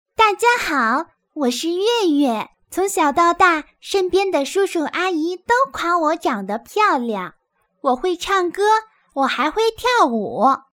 女声配音